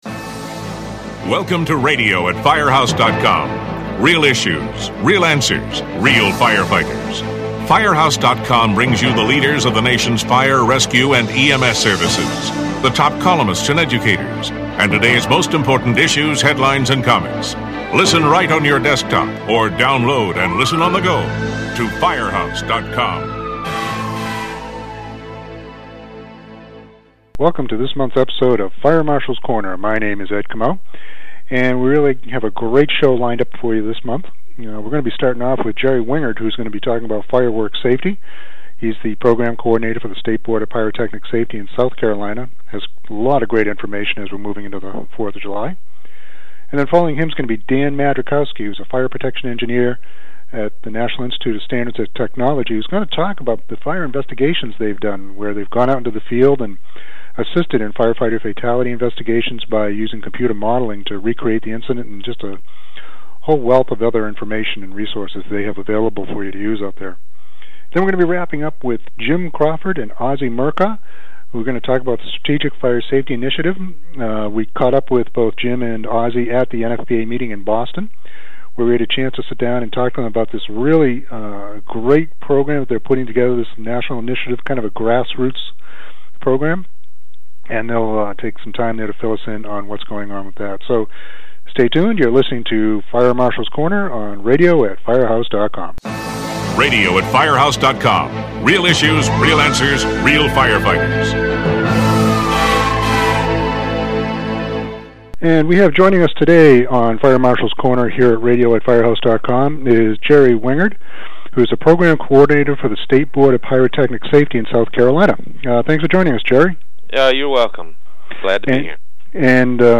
We then move into an interview that was done at the National Fire Protection Association (NFPA) show in Boston with Vancouver